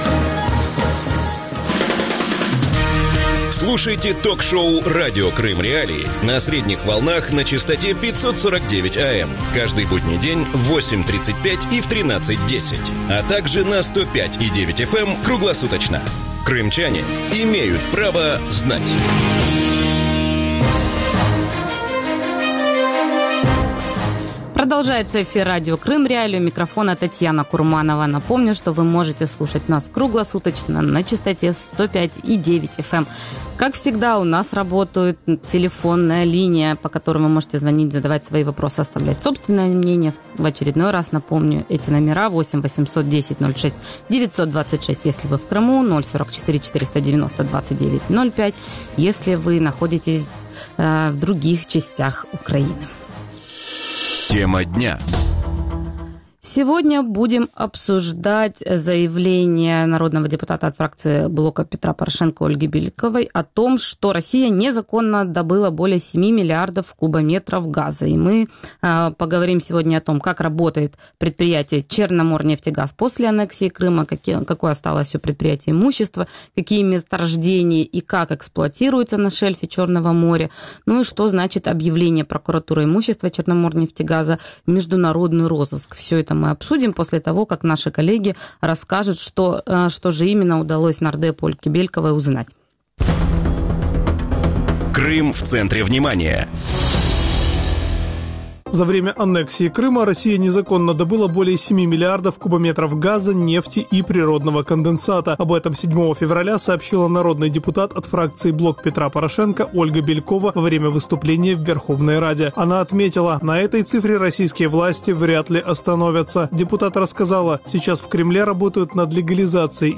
Как работает предприятие «Черноморнефтегаз» после аннексии Крыма? Какие месторождения и как эксплуатируются на шельфе Черного моря? Что значит объявление прокуратурой имущества «Черноморнефтегаза» в международный розыск? Гости эфира